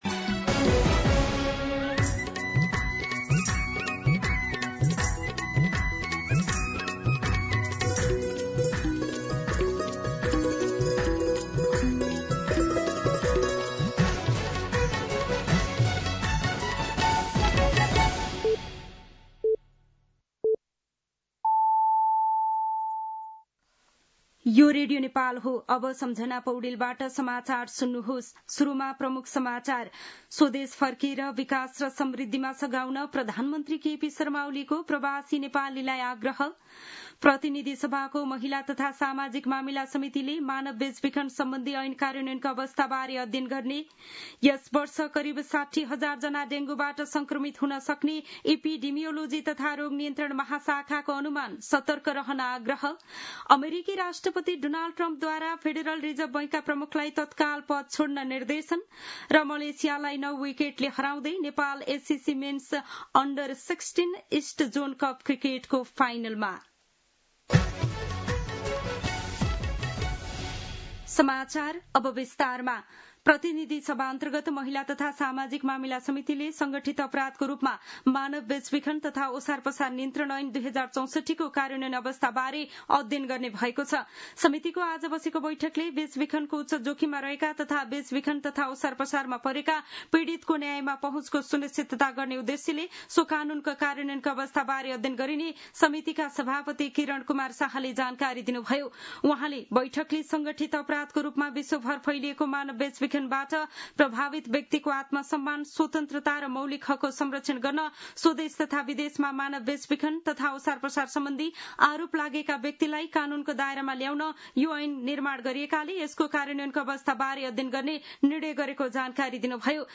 दिउँसो ३ बजेको नेपाली समाचार : १९ असार , २०८२